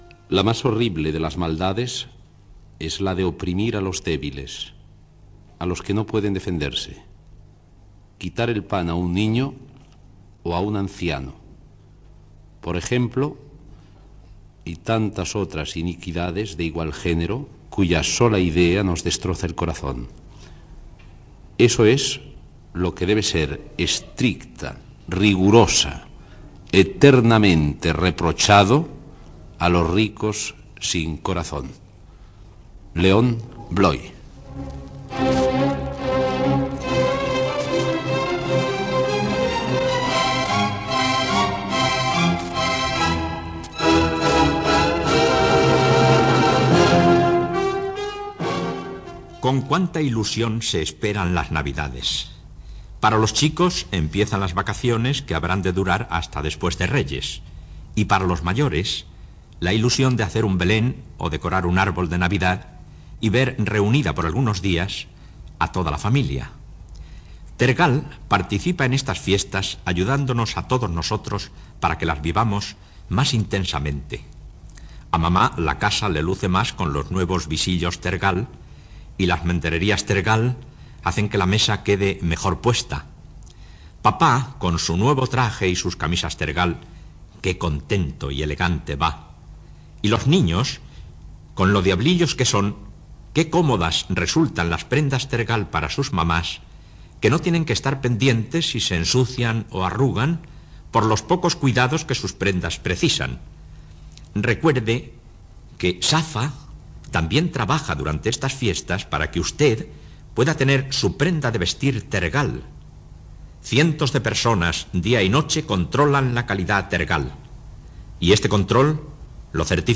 Gènere radiofònic Participació